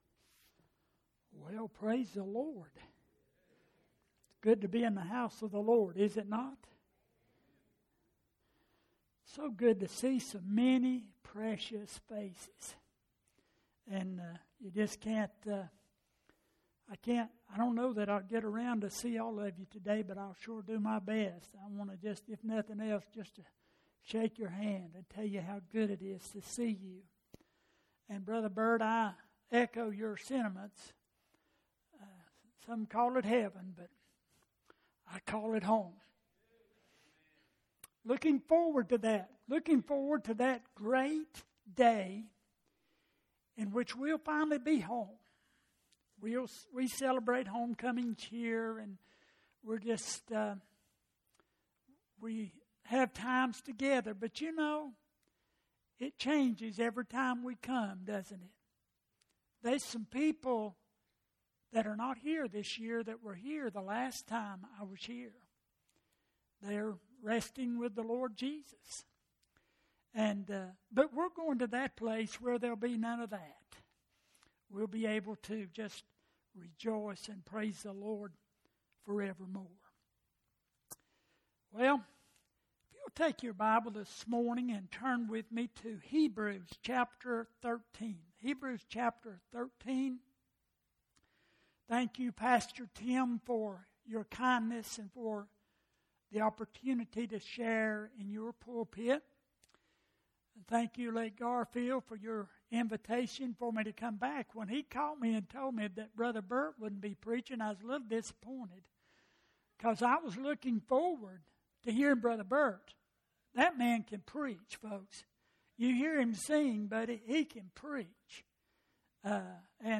Passage: Hebrews 13:8 Service Type: Homecoming